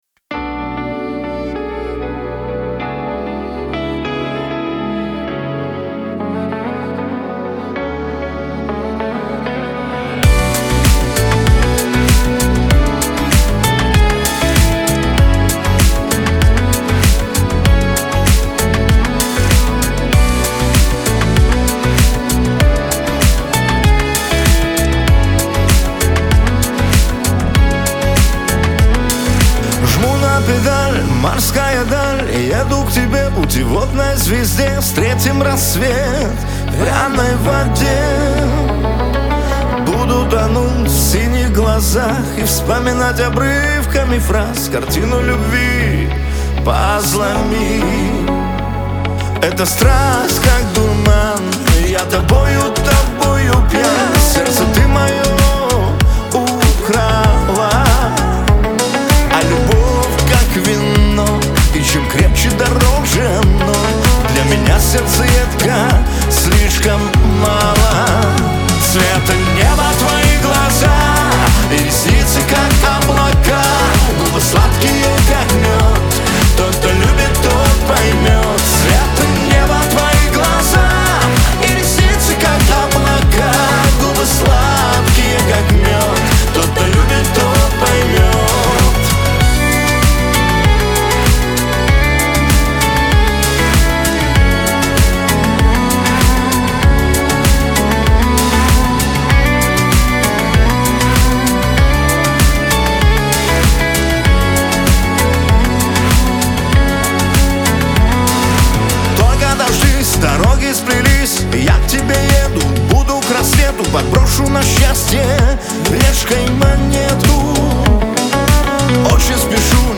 pop , Лирика